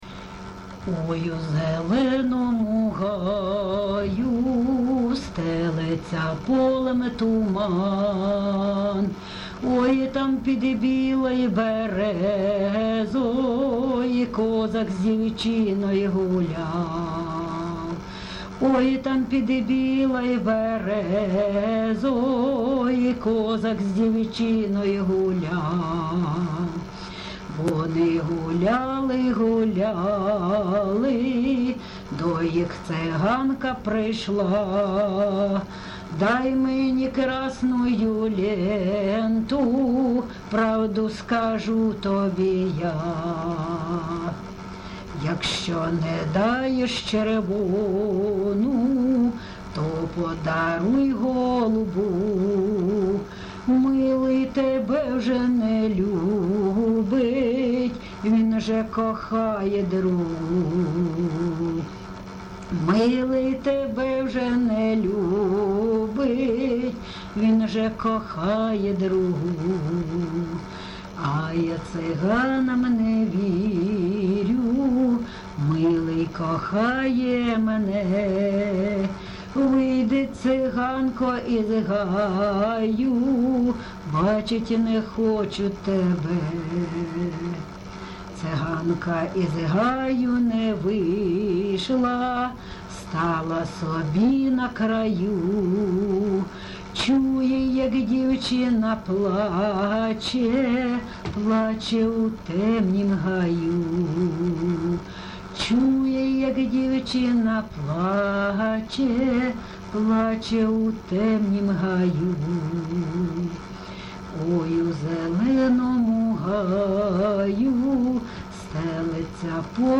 ЖанрПісні з особистого та родинного життя
Місце записус. Лозовівка, Старобільський район, Луганська обл., Україна, Слобожанщина